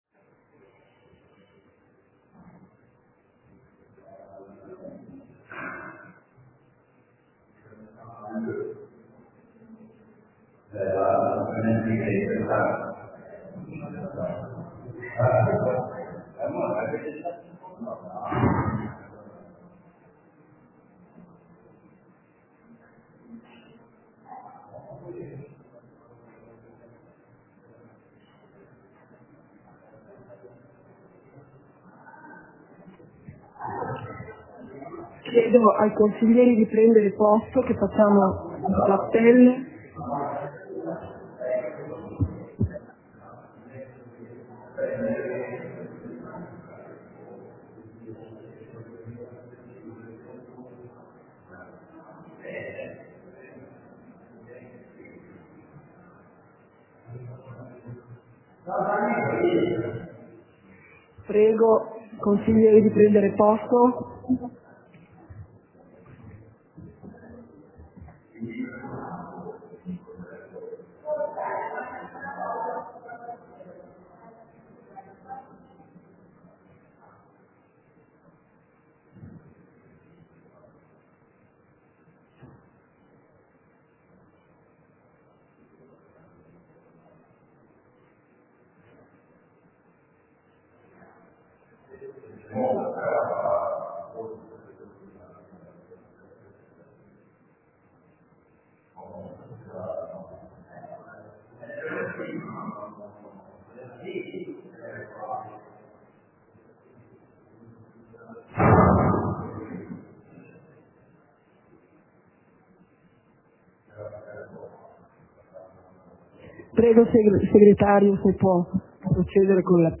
Seduta del consiglio comunale - 09.01.2023